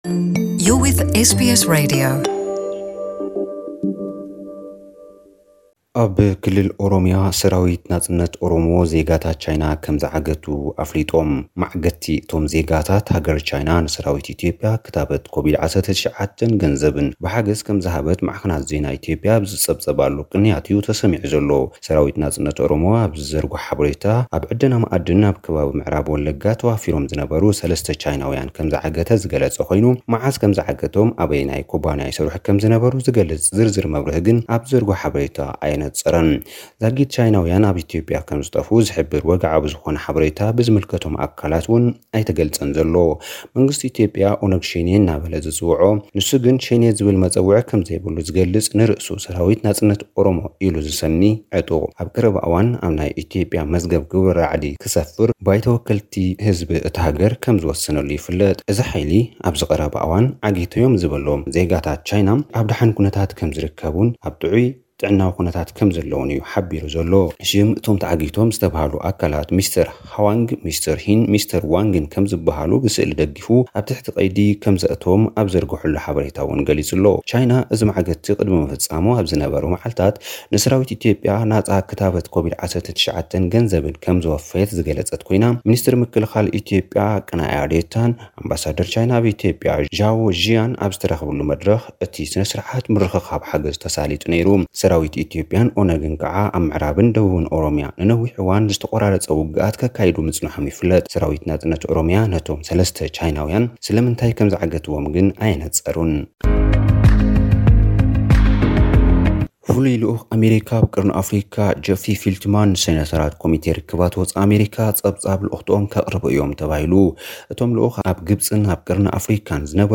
ሓጸርቲ ጸብጻባት፥